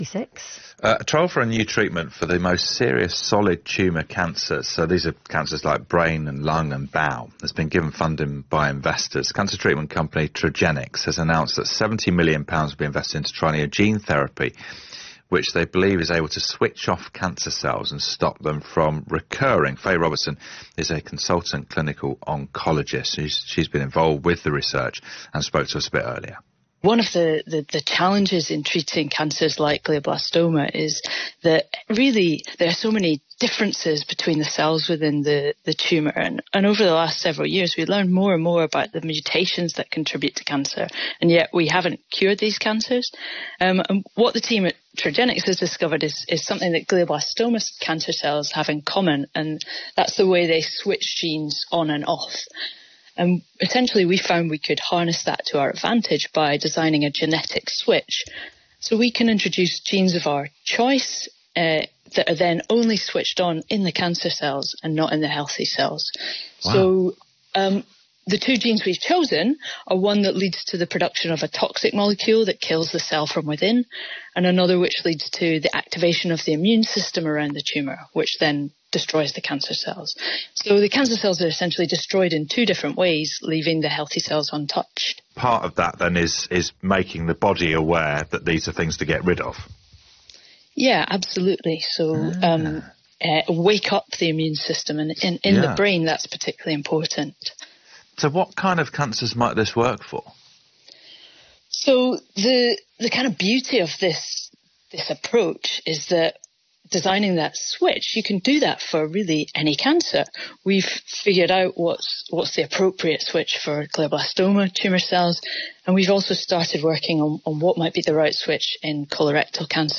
BBC Radio 5 Live interview